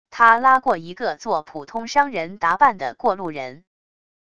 他拉过一个作普通商人打扮的过路人wav音频生成系统WAV Audio Player